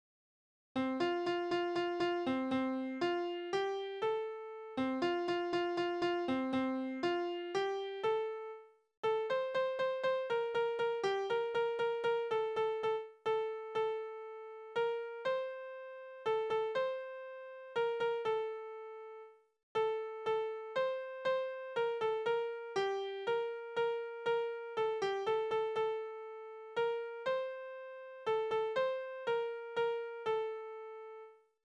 Kindertänze: Lustiger Tanz
Tonart: F-Dur
Taktart: 2/4, 3/4
Tonumfang: Oktave